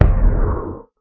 elder_hit4.ogg